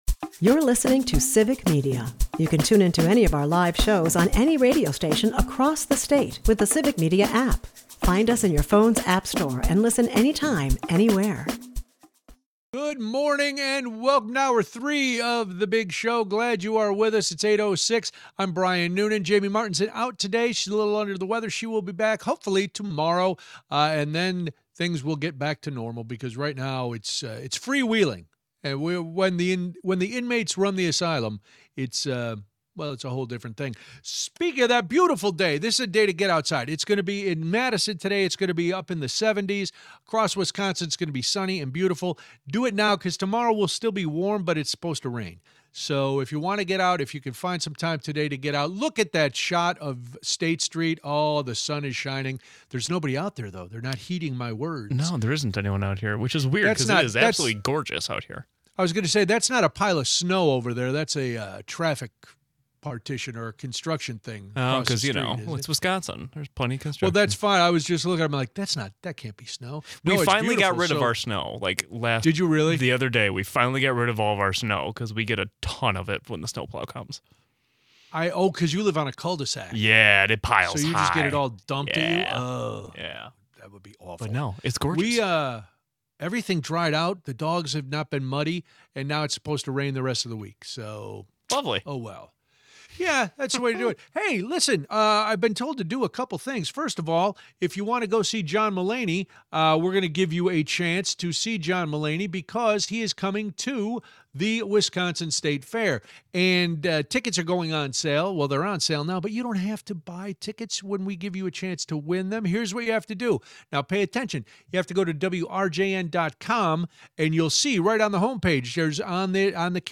Later we are joined by Wisconsin Supreme Court candidate Judge Chris Taylor. She tells us about her campaign and some of the concerns she has with today’s government overreach.